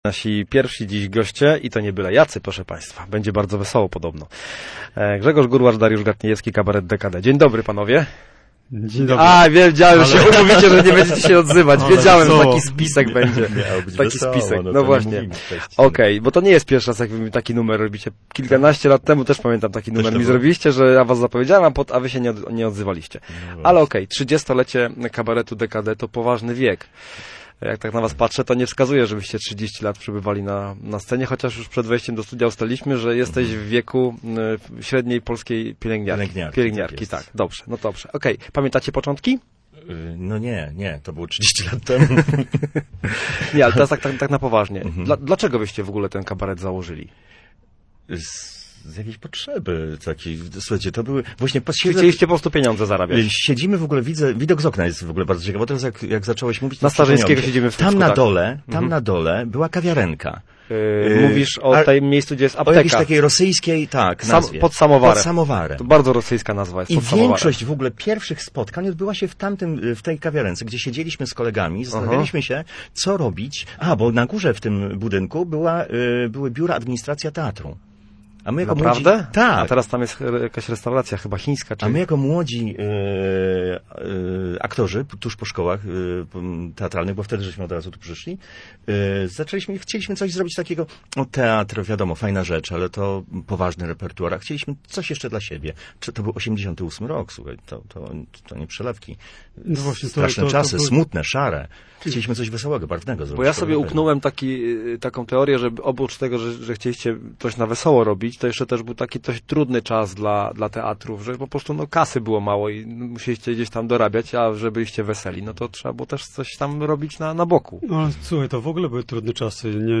Artyści byli gośćmi miejskiego programu Radia Gdańsk Studio Słupsk 102 FM.